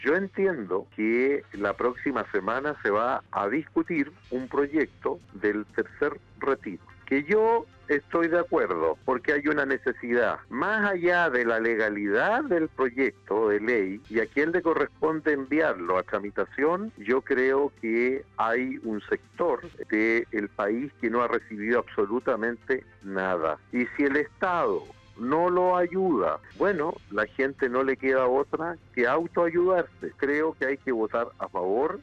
En entrevista con Radio Sago, el Senador por la región de Los Lagos, Iván Moreira, se refirió a un posible nuevo retiro del 10 por ciento desde el fondo de pensiones.